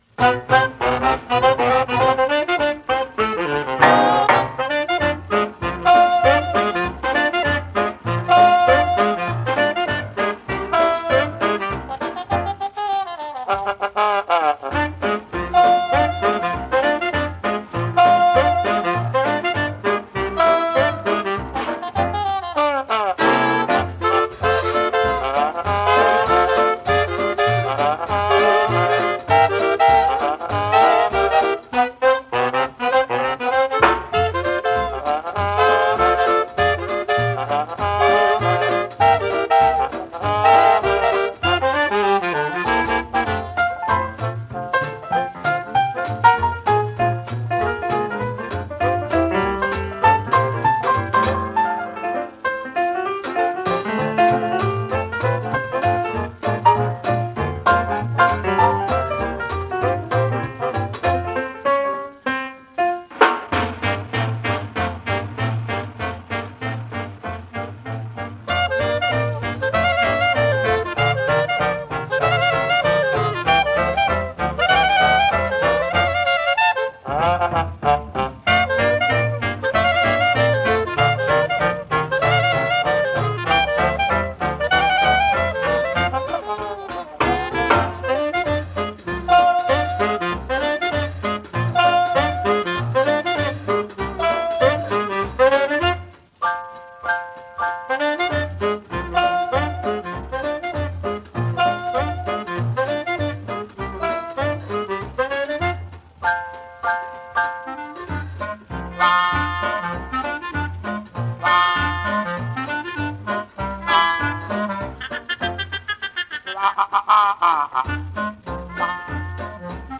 But what there is comes packed with energy and wit.